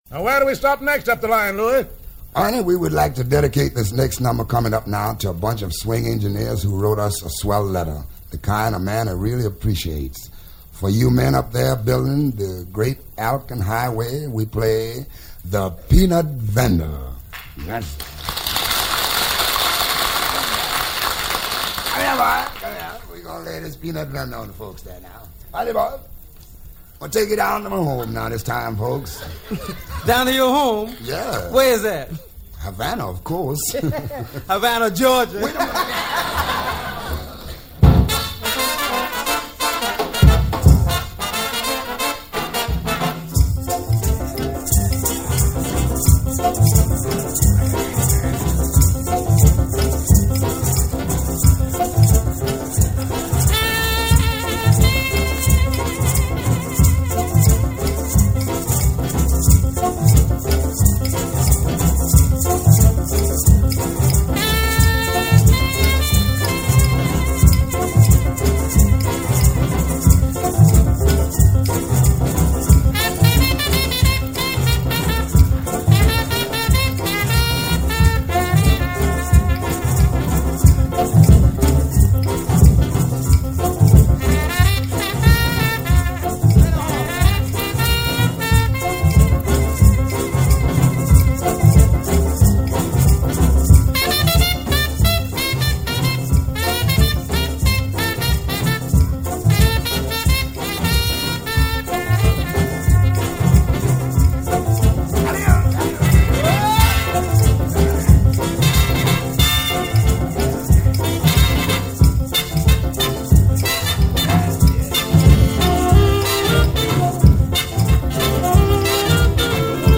Jazz, New Orleans Jazz